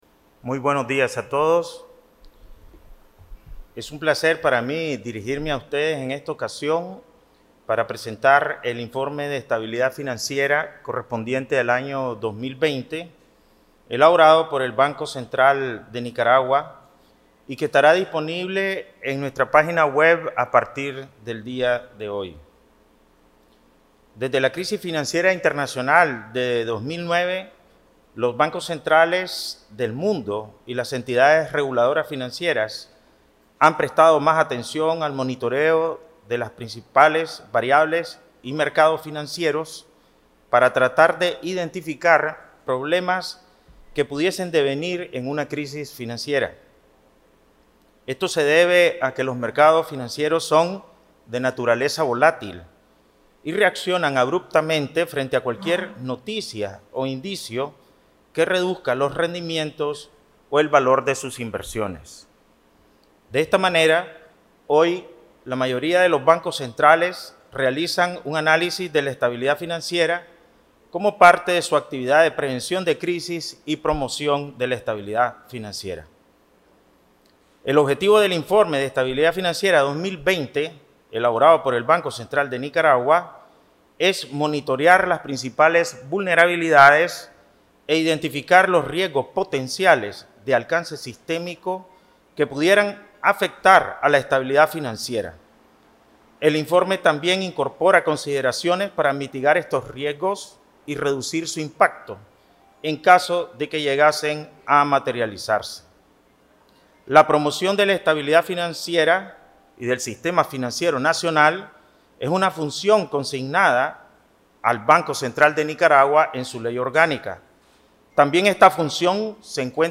Audio: Palabras del Presidente del BCN, Cro. Ovidio Reyes R.